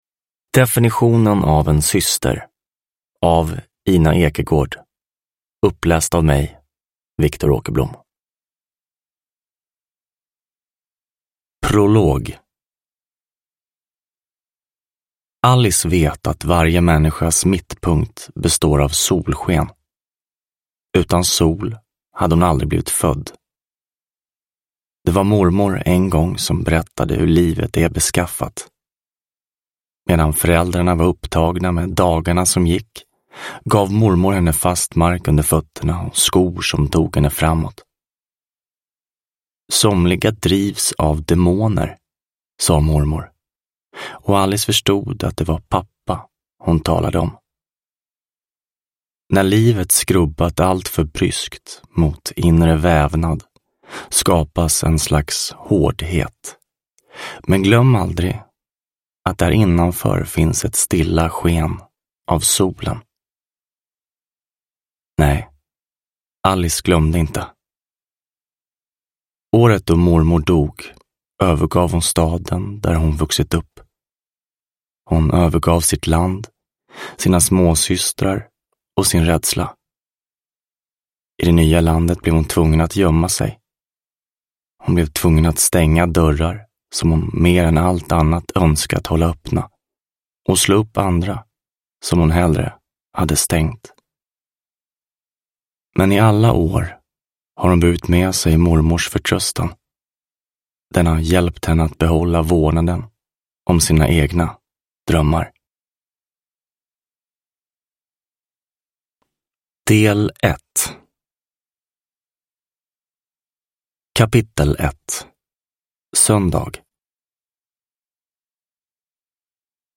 Definitionen av en syster – Ljudbok – Laddas ner